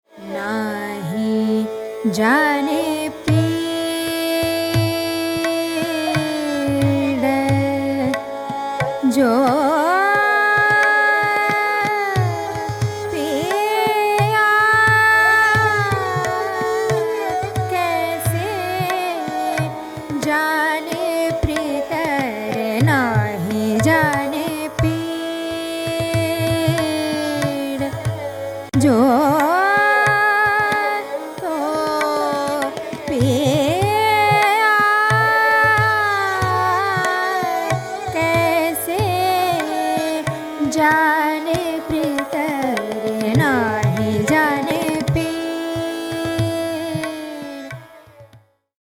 Aroha: SmG, GMPNS
Avroh: SNDPMG, MGRS
Pakad: e.g. SNS mGP; MGMGRS
[bandish, e.g. 1:43] (S)NS (M)GMP, (P)MG G(RG), GMP/N(P), (P)S(NSDnDn)P, P P(ND)PM, MPM(G) (M)G(R)S S, (S)NS (M)GMP
• Tanpura: Sa–Pa (+Ni)